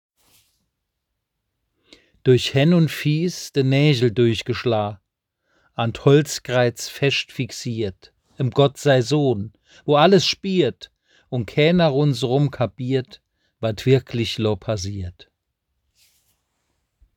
Es enth�lt eine Tonversion der obenstehenden moselfr�nkischen Kreuzwegbetrachtung Sie k�nnen es unter diesem Link abrufen.